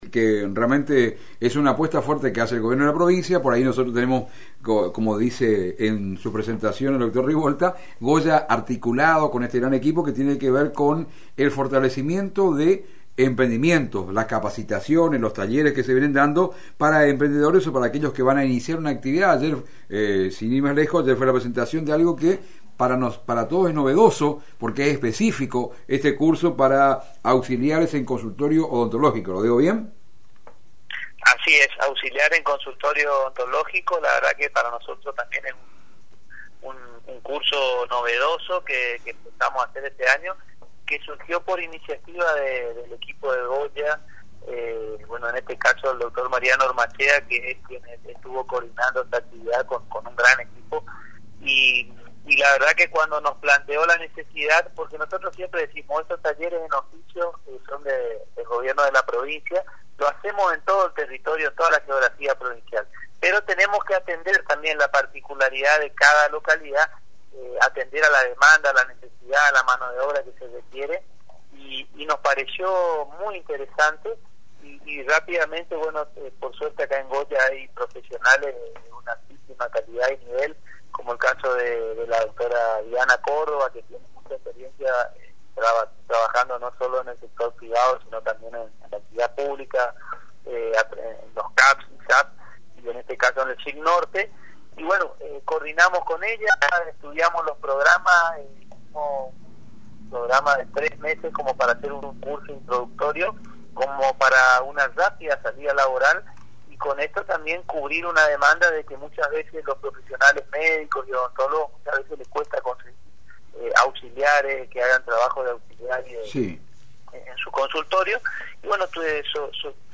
El Subsecretario de Trabajo en declaraciones a LT6 «Radio Goya” destacó la importancia y la necesidad de capacitarse en temas que ayudaran a una mejor búsqueda laboral o generar sus espacios productivos